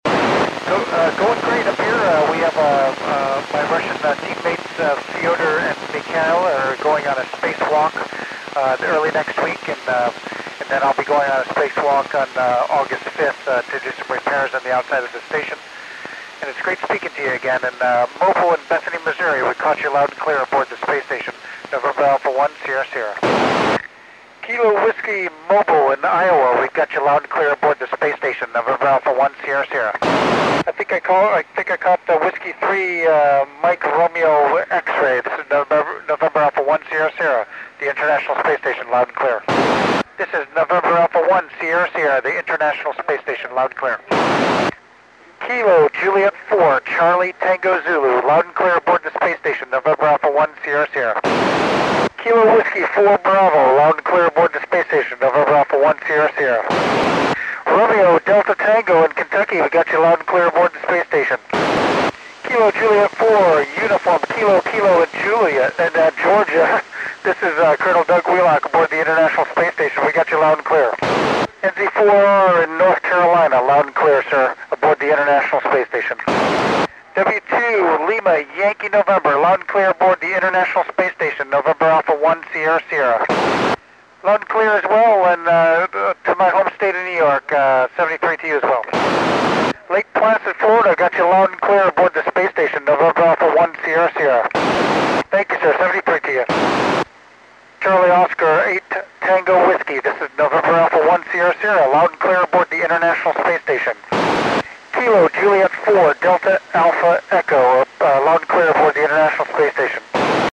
When I record, I leave the squelch off, and when I edited the recording, I deleted most of the squelch noise to reduce the size of the recording, so the "squelch tail" separates transmissions by Col. Wheelock.